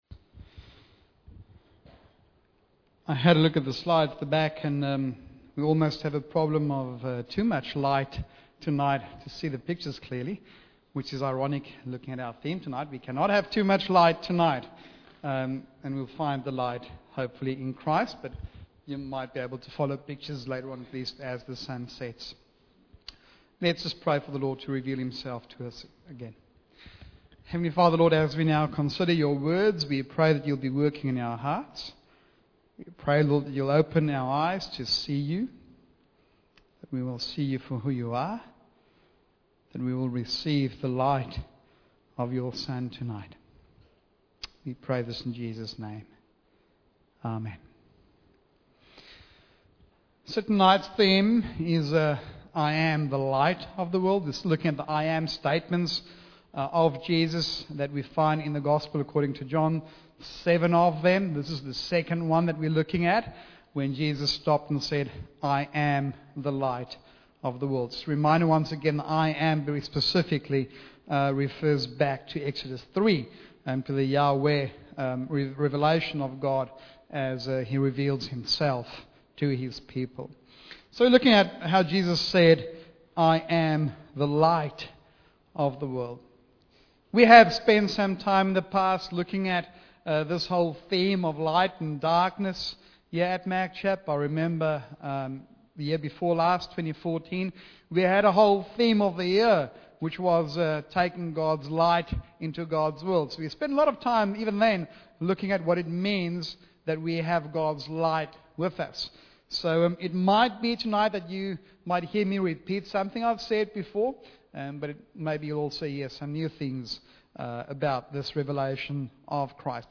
Bible Text: John 8:12-9:5 | Preacher